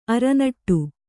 ♪ aranaṭṭu